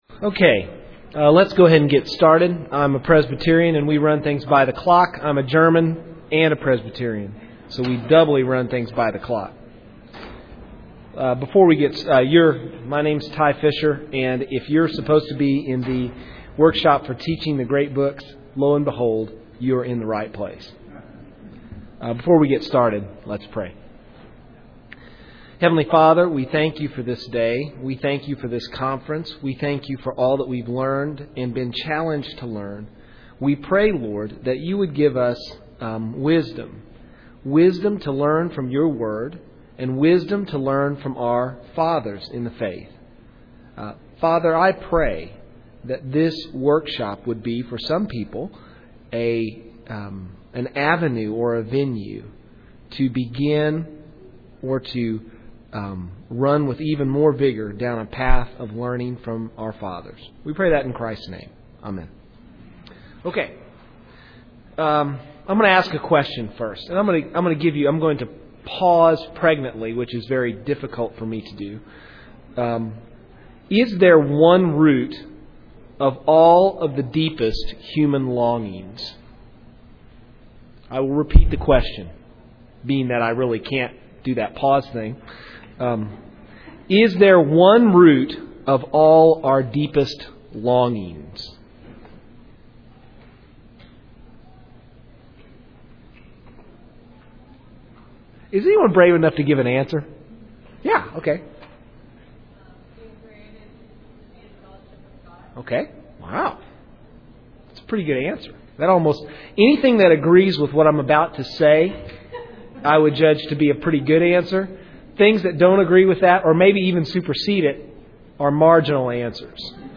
2007 Workshop Talk | 0:58:06 | All Grade Levels, Literature
Mar 11, 2019 | All Grade Levels, Conference Talks, Library, Literature, Media_Audio, Workshop Talk | 0 comments